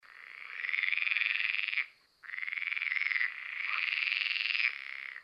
Pelophylax kl. esculentus
Il canto è il ben conosciuto gracidio. Ogni gruppo dura circa 1.5 secondi, ed è formato da una rapidissima sequenza di impulsi, 20-30/sec in R. esculenta, 30-45/sec in R. lessonae che canta con voce relativamente più sommessa.
The song is the well-known croak. Each group lasts about 1.5 s, and is made of a very fast sequence of pulses, 20-30/sec in R. esculenta, 30-45/sec in R. lessonae that sings with a relatively feebler voice.
SAMPLE_Rana_esculenta.mp3